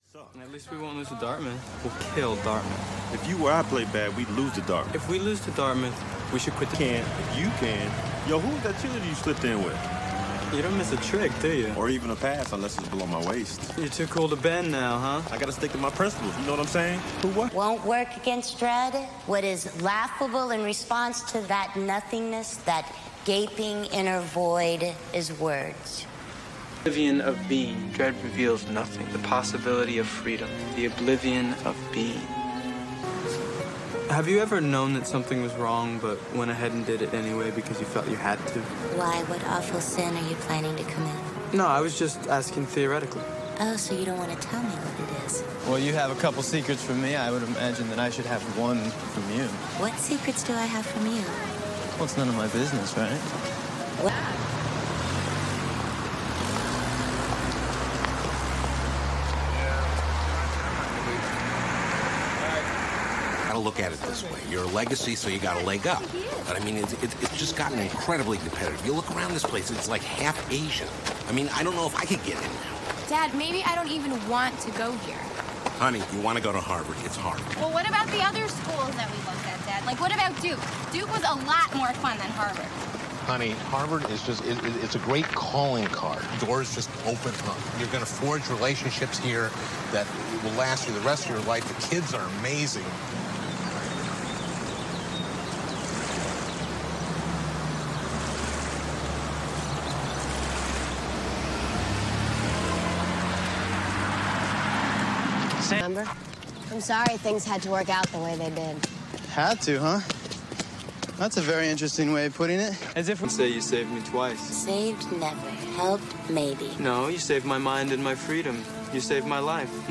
I must say that the quality of the movie and sound is terrible even for 2001.